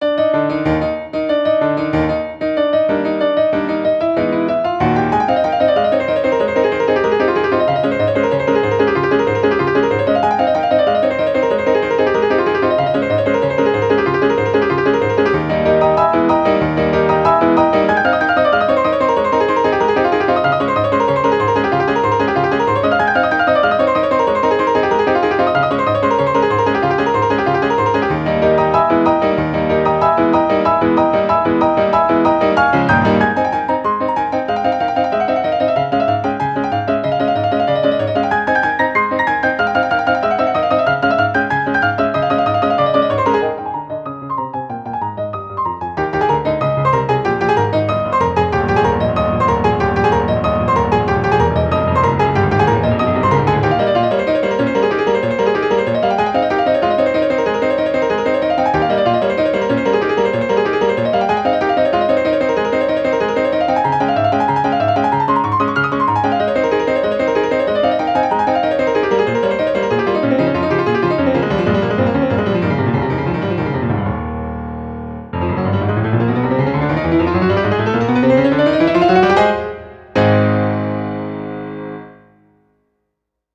ogg(R) - カオス にぎやか 激しい